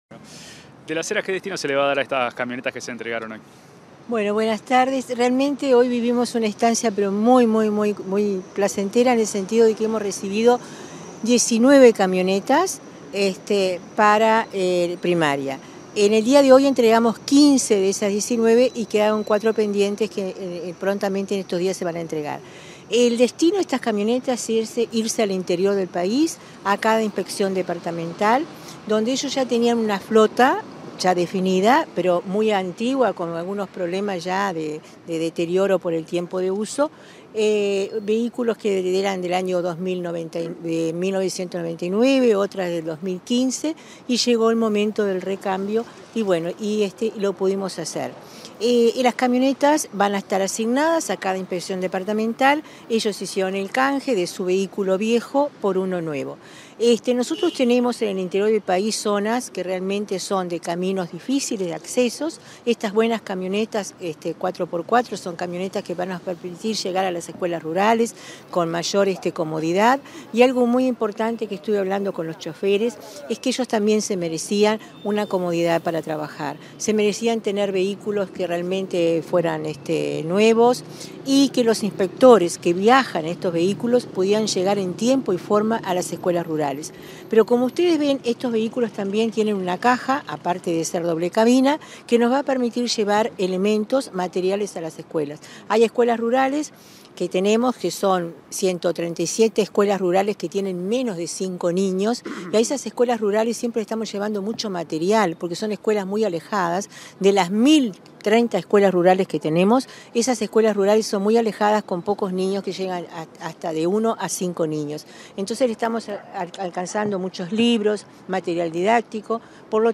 Declaraciones de la directora general de Educación Inicial y Primaria, Olga de las Heras | Presidencia Uruguay
Declaraciones de la directora general de Educación Inicial y Primaria, Olga de las Heras 10/07/2024 Compartir Facebook X Copiar enlace WhatsApp LinkedIn Tras la presentación de 15 camionetas nuevas, este 10 de julio, que serán entregadas a las distintas inspecciones departamentales del subsistema en todo el país, la directora general de Educación Inicial y Primaria (DGEIP), Olga de las Heras, realizó declaraciones a la prensa.